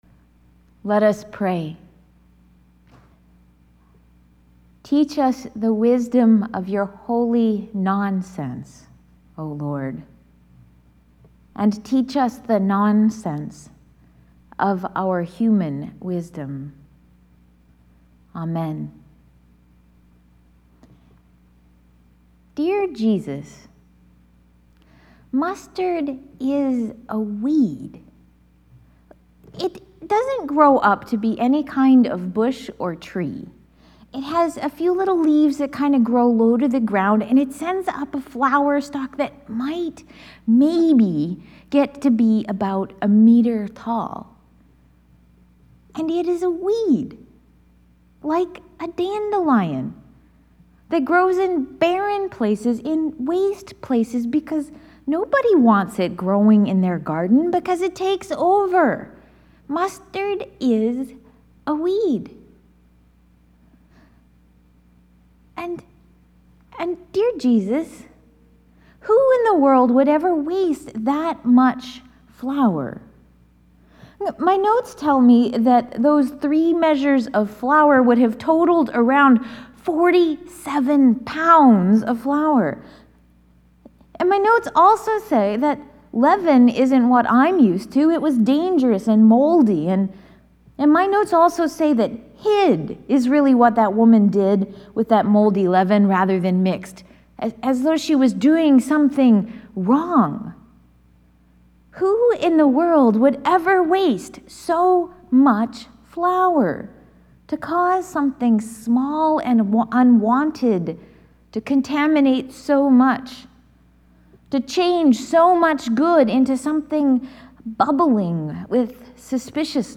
Audio of sermon: